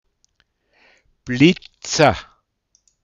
Wortlisten - Pinzgauer Mundart Lexikon
Feier mit Alkohol Blitza, .m